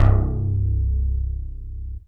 SYNTH BASS-1 0011.wav